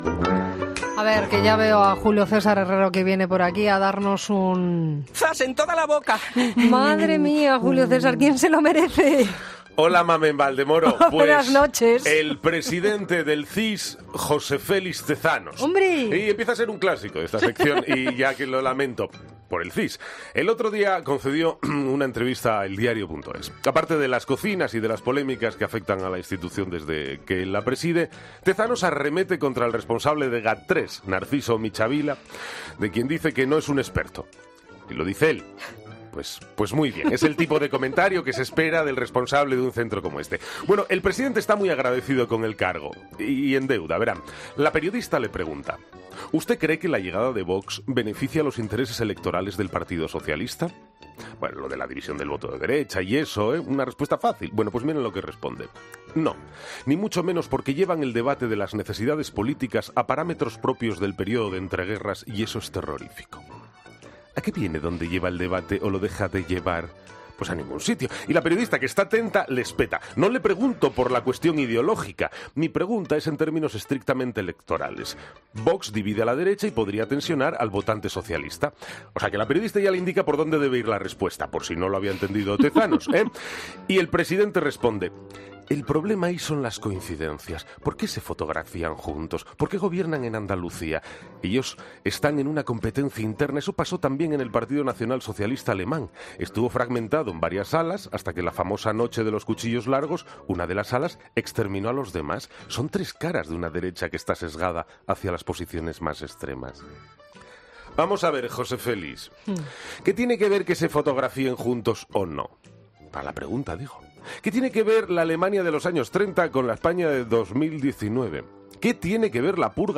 el periodista y colaborador del programa